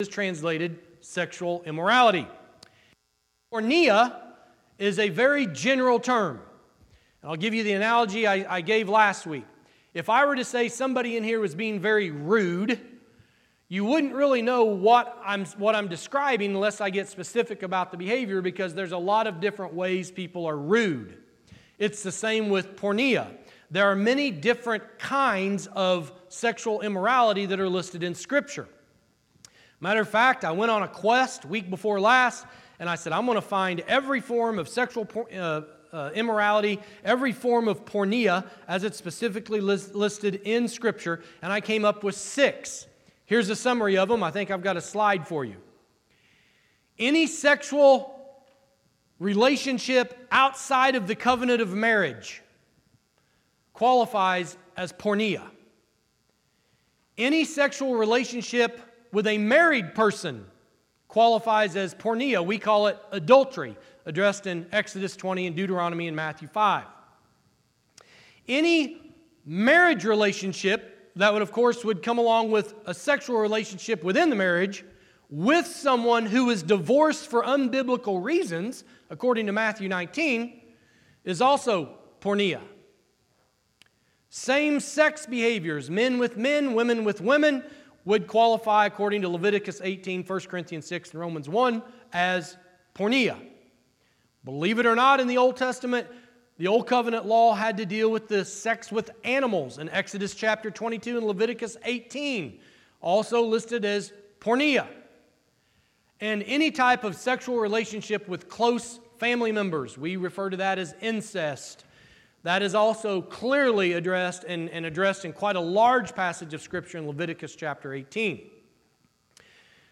Church in Action Sermon Podcast